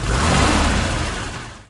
autobahn_motor.ogg